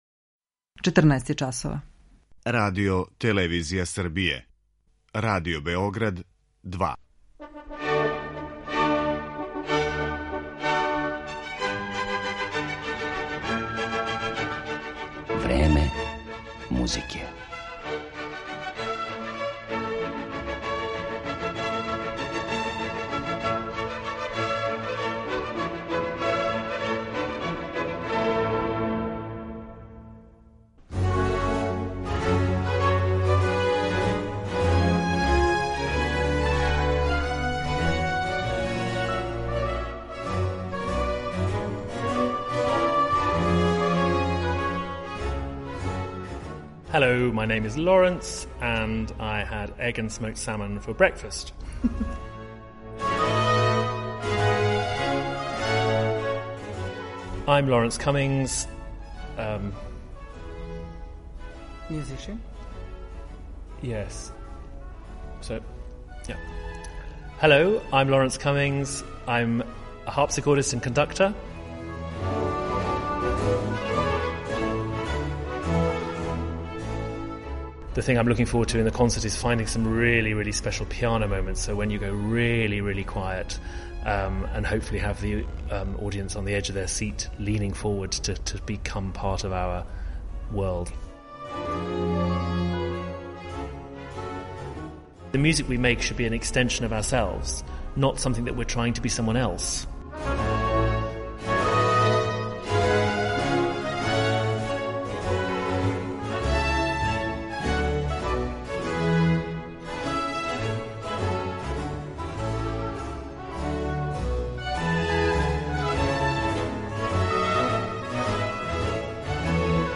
У дашањој емисији представићемо га превасходно као тумача Хендлових дела, али и кроз ексклузивни интервју.